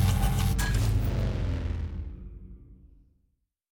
gauss_siege_release.wav